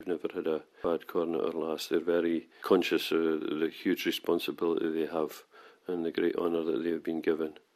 The Hawick Common Riding for 2015 got underway this morning with the chase and songs at the Hut.